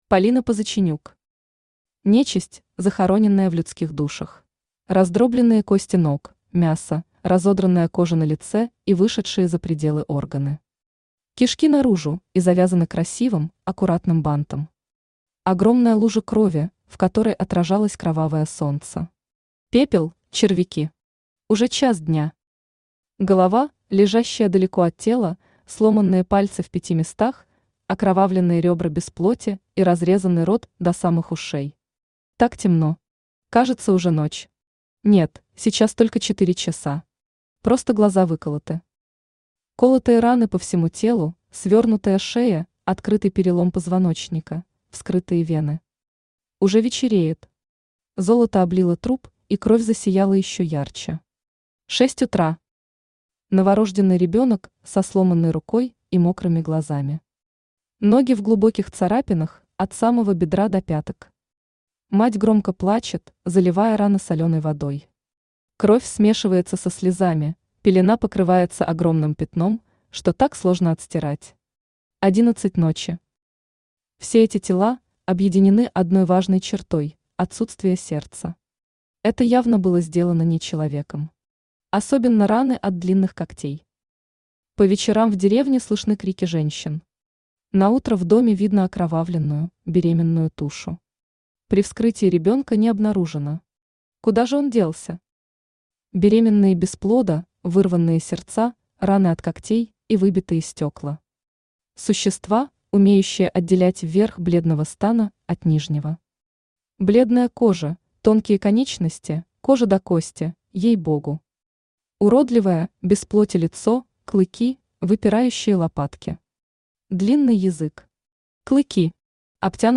Аудиокнига Нечисть, захороненная в людских душах | Библиотека аудиокниг